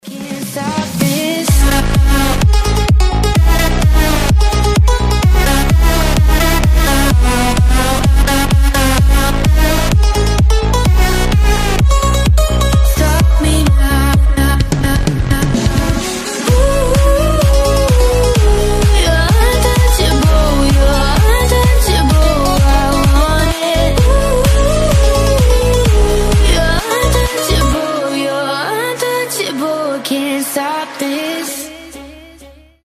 громкие
восточные мотивы
женский голос
Electronic
EDM
Big Room
Энергичная хаус-музыка